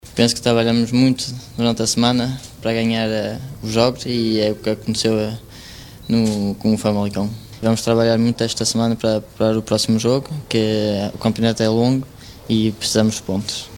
Declarações do gilista aos órgão de comunicação do clube.